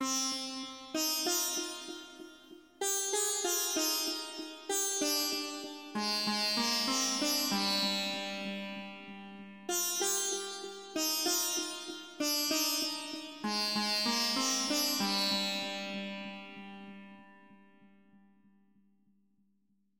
描述：适当的西塔琴循环
标签： 96 bpm Hip Hop Loops Sitar Loops 3.36 MB wav Key : Unknown
声道立体声